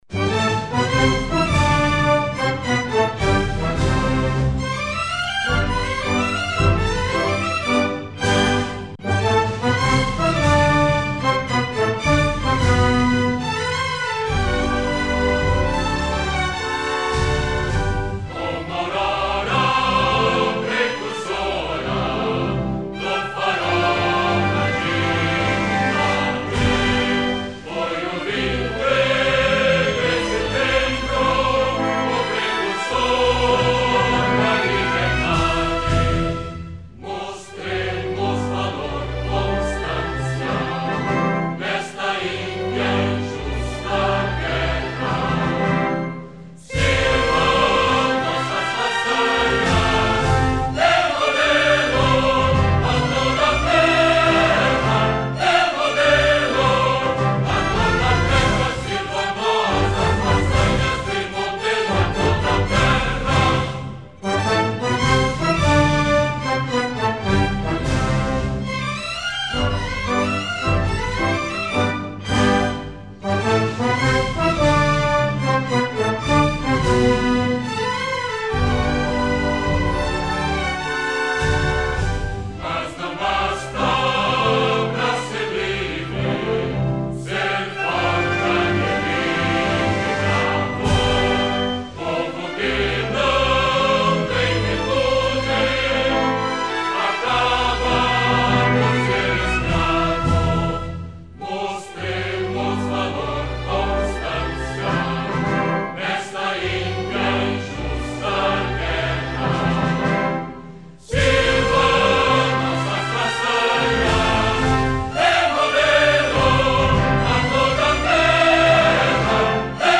2005-10-05-hino-do-rs-cantado.mp3